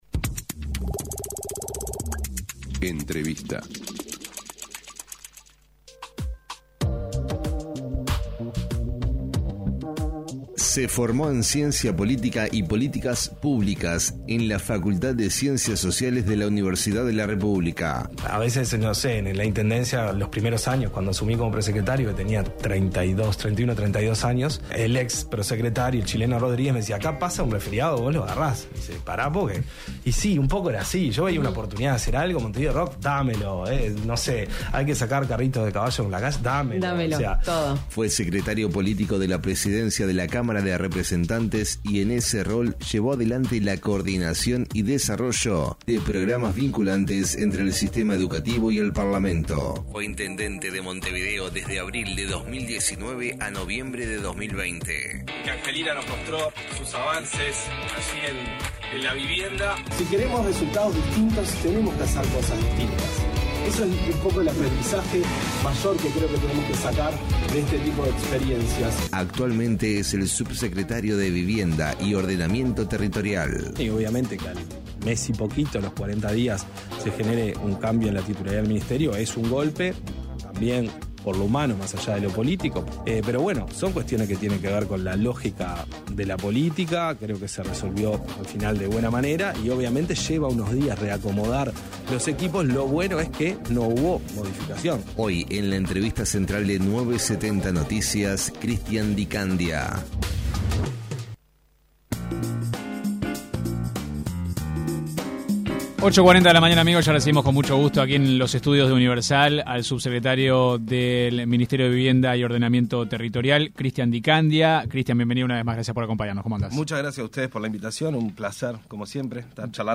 El subsecretario de Vivienda, Christian Di Candia destacó en entrevista con 970 Noticias, la diferencia entre los programas habitacionales: mientras el Plan Avanzar se centra en licitar y construir, el Plan Juntos avanza más lentamente pero con un fuerte acompañamiento social a las familias.